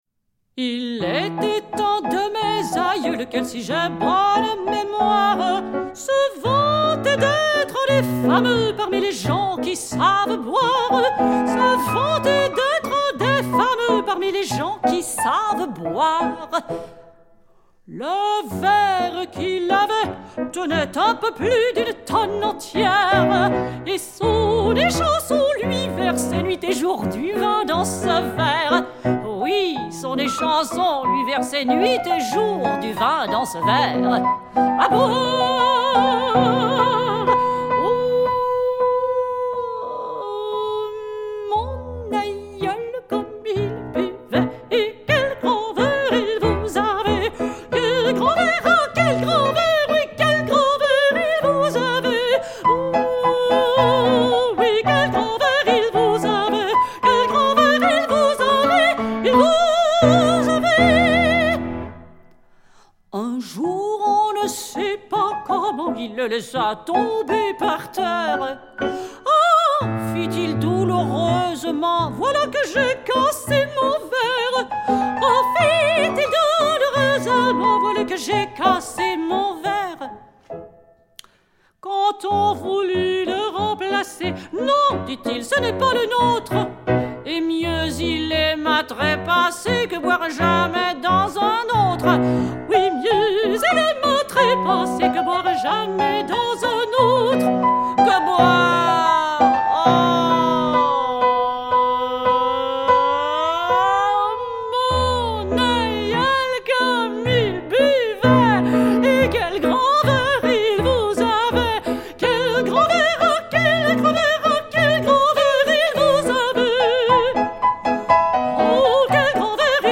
chant
piano
elle passe d'un climat musical à l'autre avec souplesse et élégance.